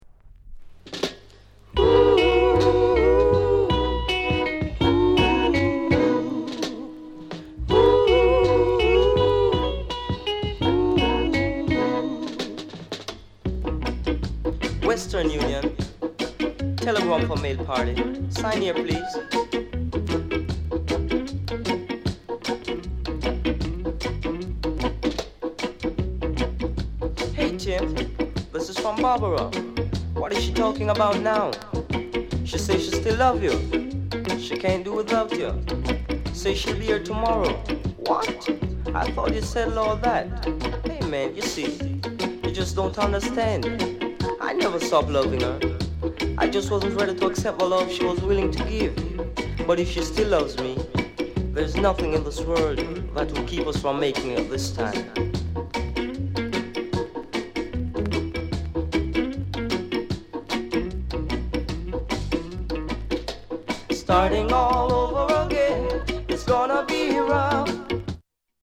NICE SOUL COVER